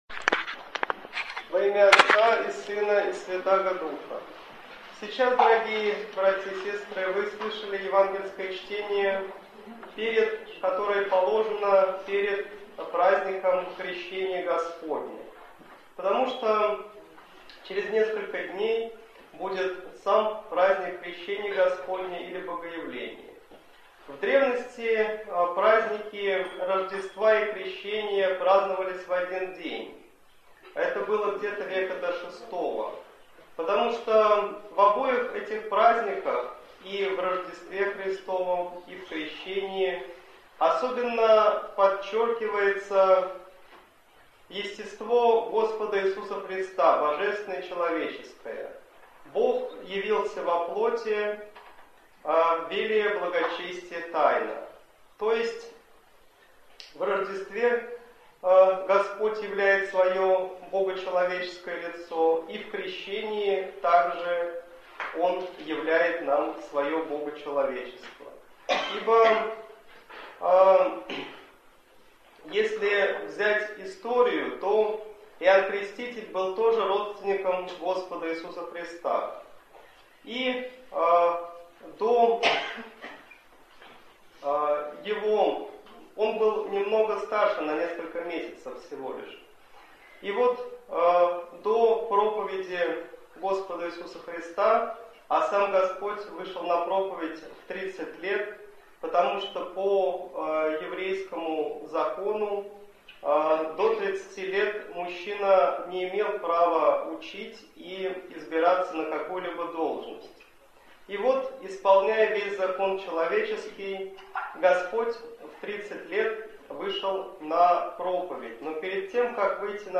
Слово в неделю 34-ю перед Богоявлением